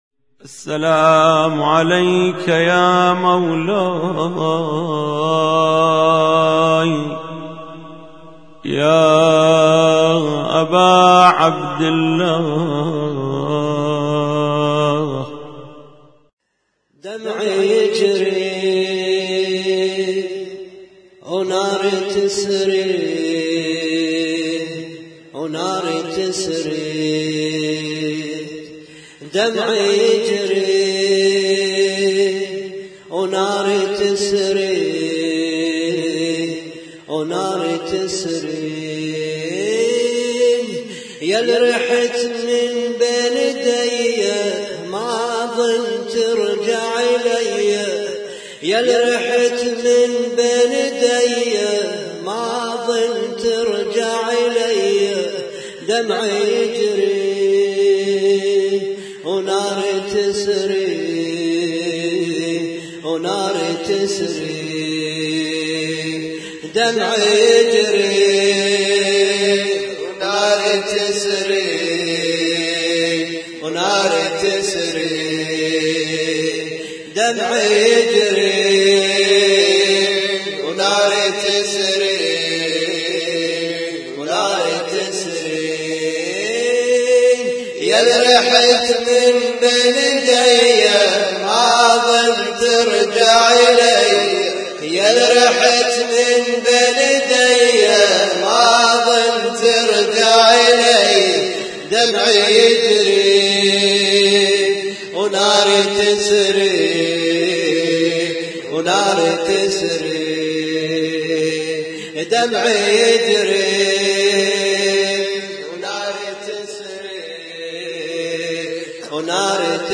اسم النشيد:: لطم مشترك - ليلة 8 محرم 1436